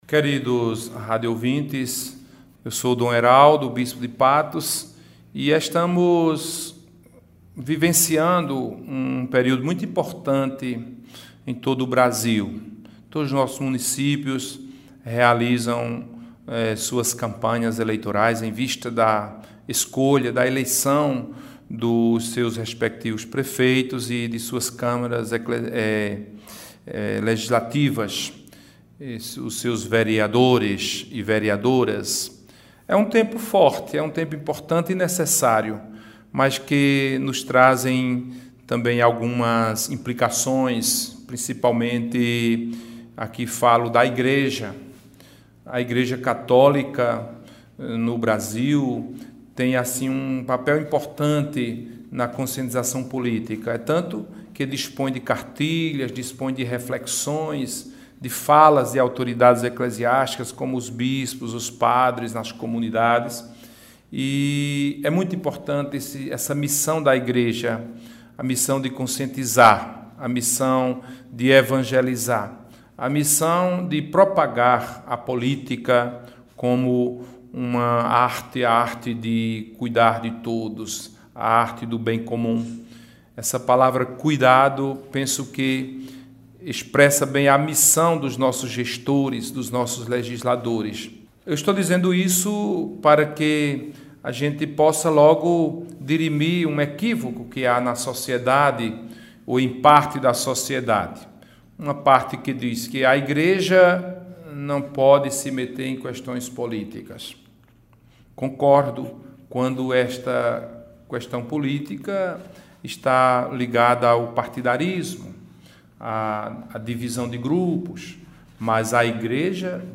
Em uma entrevista a Rádio Espinharas de Patos nesta quinta-feira (12), o bispo diocesano de Patos, Dom Eraldo Bispo da Silva, falou sobre a importância da Igreja participar, junto com a população, do processo de formação dos fieis para o voto consciente, responsável e independente.